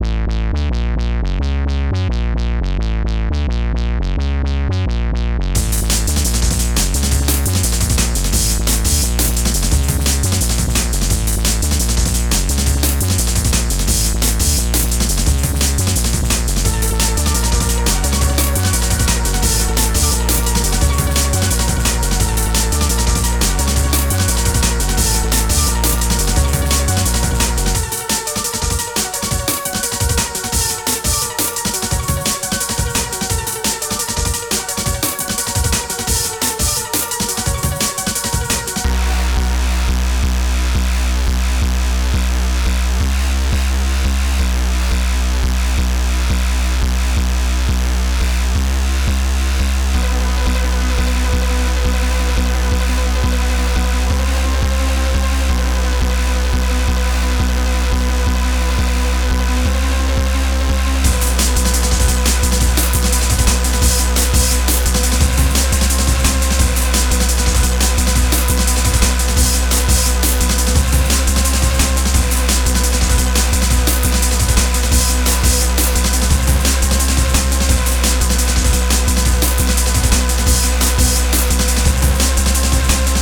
funk.mp3